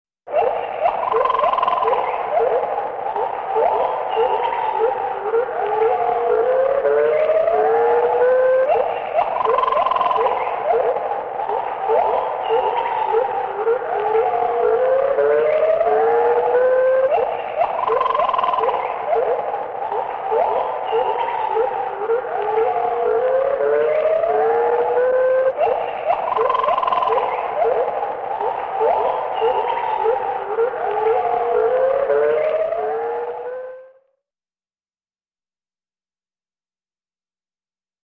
Звуки ночного леса